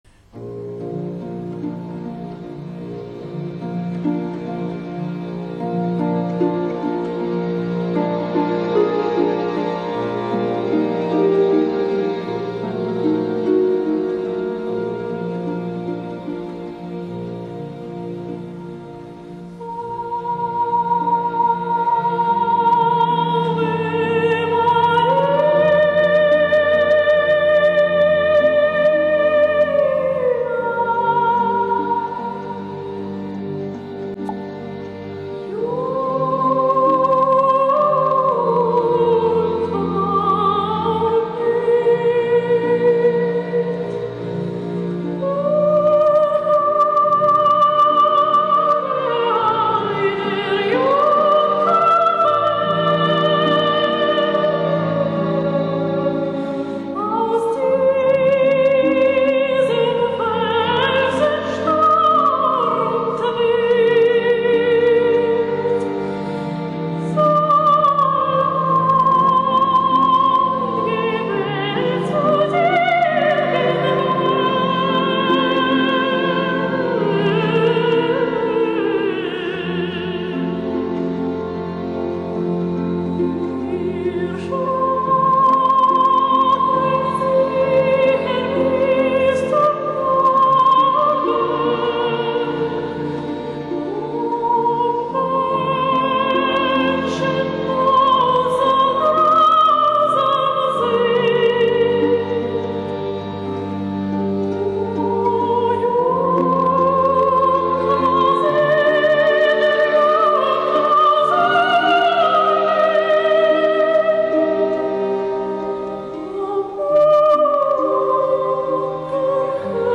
Колоратурное сопрано